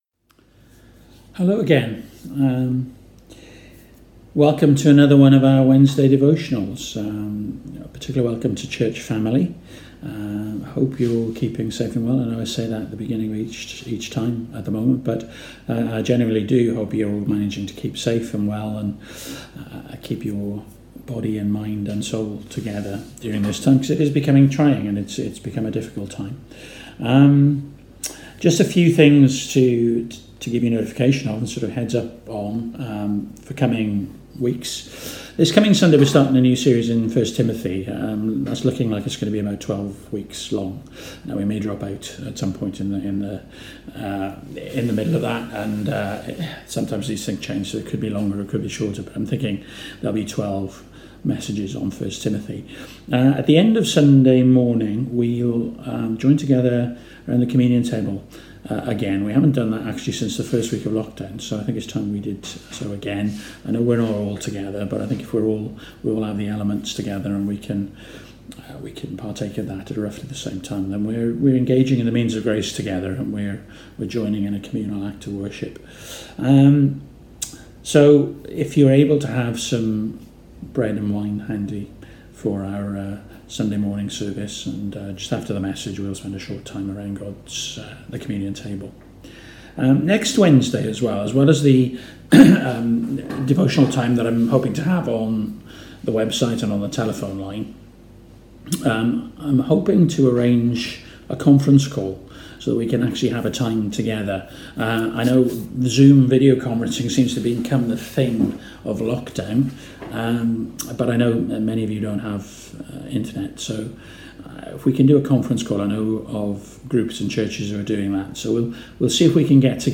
Series: Wednesday Devotional